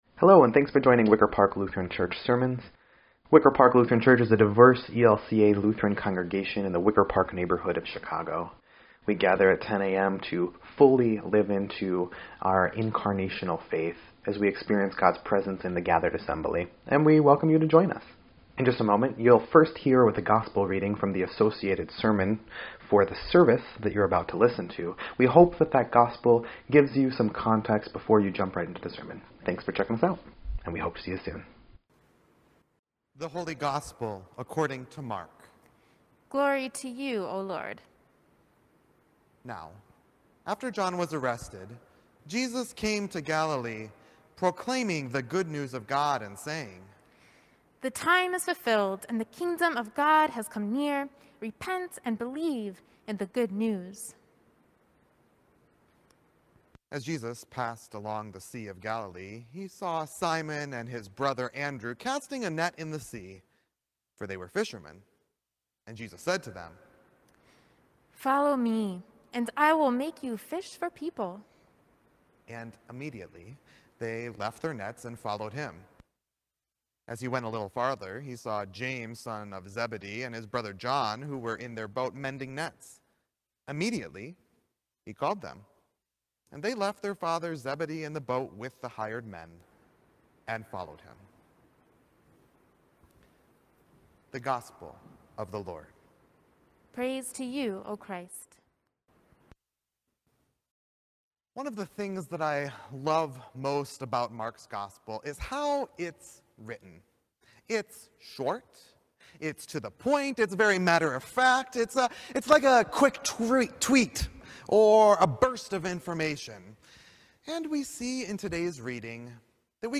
1.24.21-Sermon_EDIT.mp3